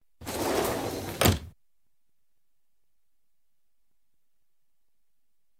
doorsclosed.wav